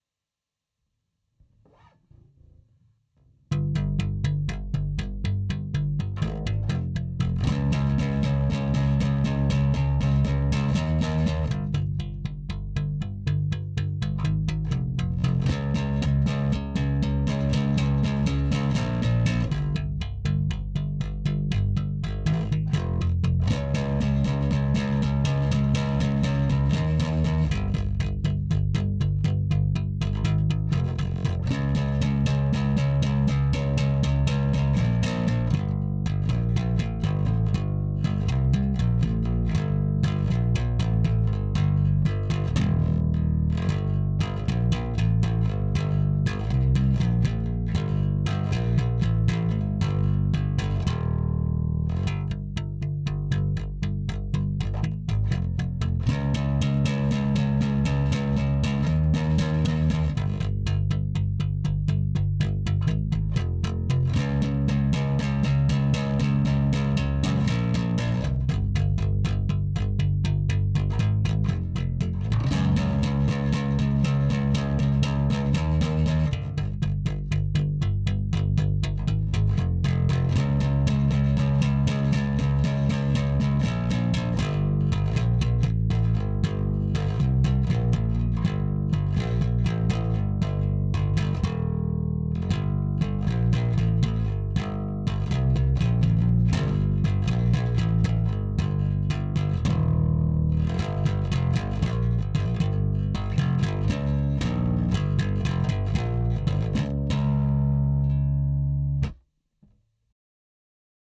Stoner Rock P-Bass??? Man entschuldige das schludrige Spiel. War kurz one-take hingespielt ohne Click um mal den Sound zu demonstrieren (und die Basslines selbst hab ich auch grad erst gelernt) Anhänge Stoner Rock maybe.mp3 1,7 MB